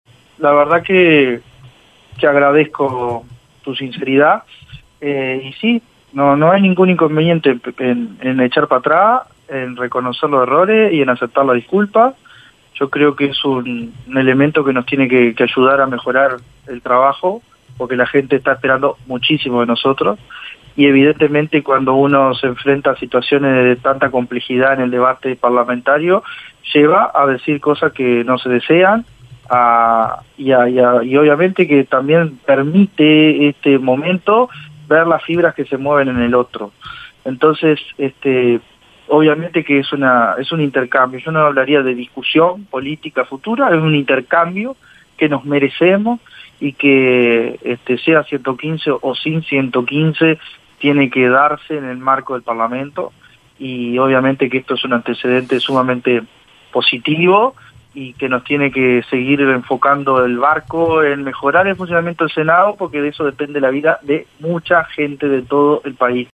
Da Silva le pidió perdón a Nicolás Viera por el exabrupto mientras Viera era entrevistado en Radio Universal